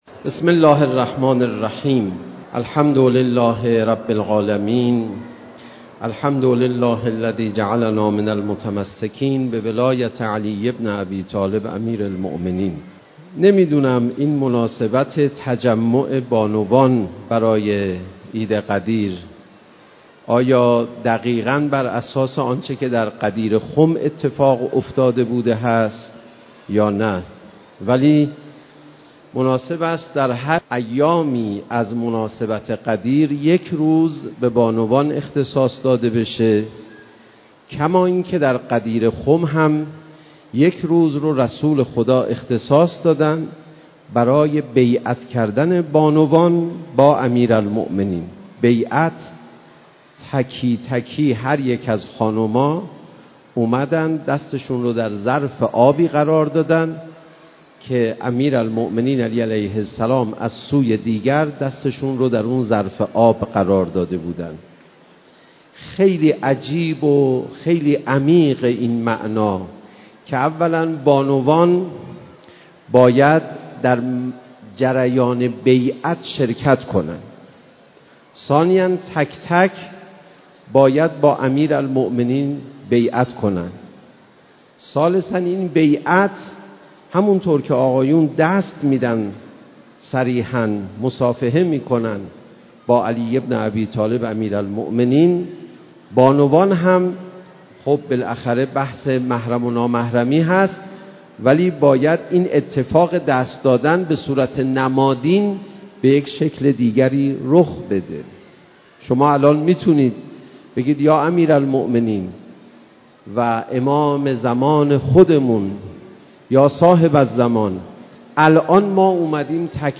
مکان: حرم حضرت معصومه(س) مناسبت: عید غدیر - اجتماع بزرگ دختران و بانوان جهان اسلام از 120 کشور